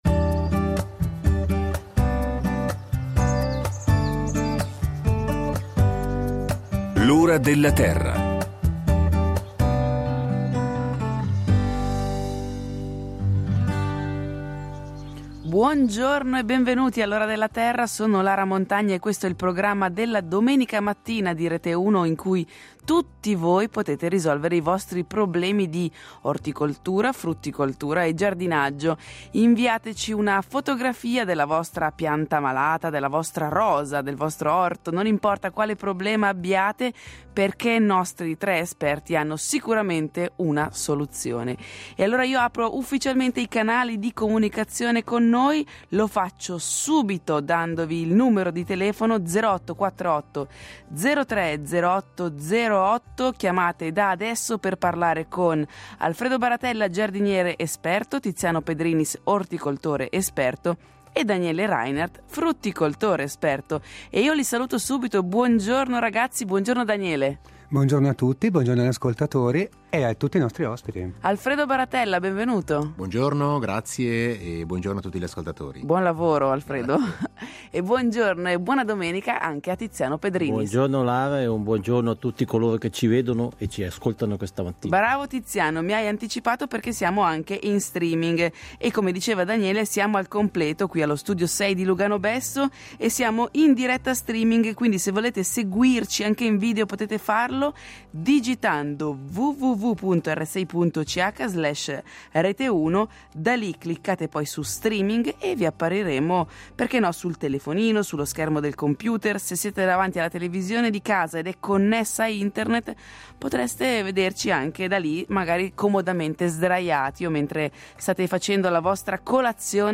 In studio gli esperti del programma risponderanno alle domande del pubblico da casa.